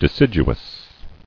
[de·cid·u·ous]